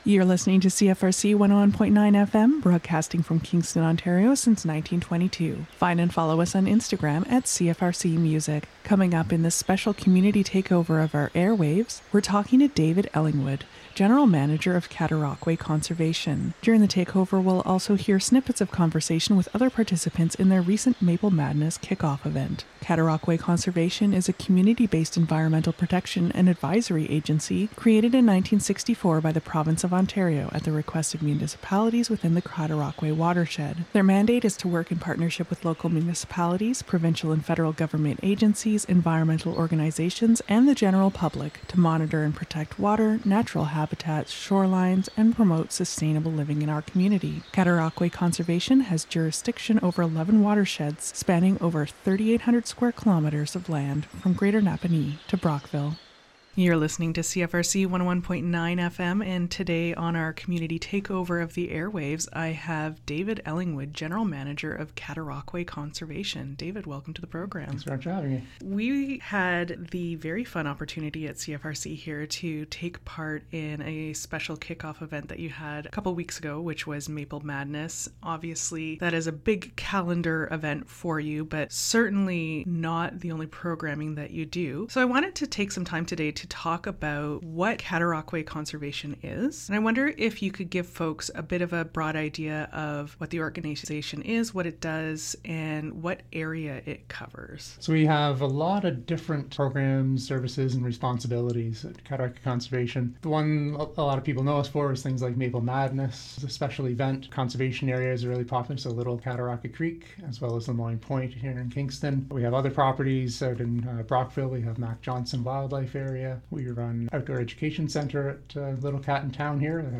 We’ll also hear snippets of conversation with other participants in their recent Maple Madness launch event.